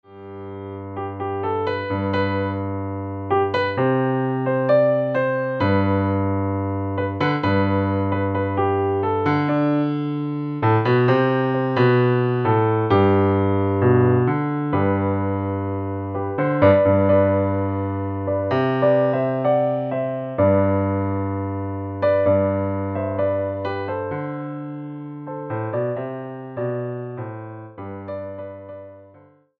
• 🎼 Tonalidad: G